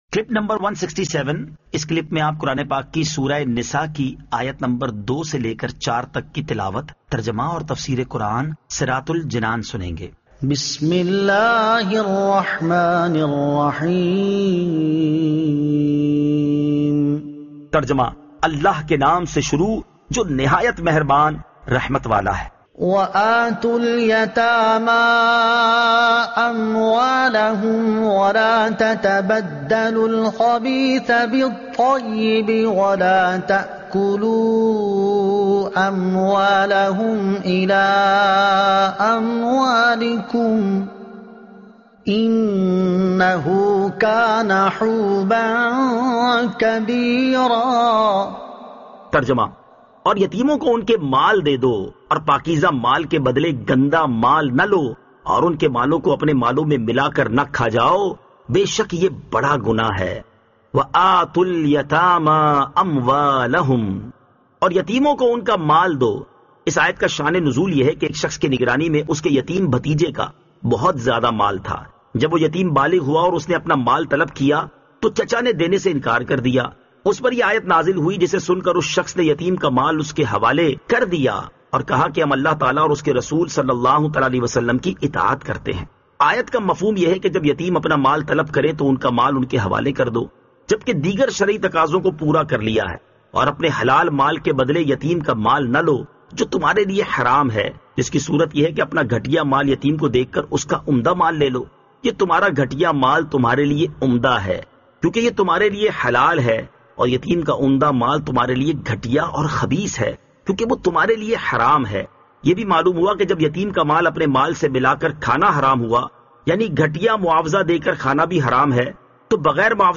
Surah An-Nisa Ayat 02 To 04 Tilawat , Tarjuma , Tafseer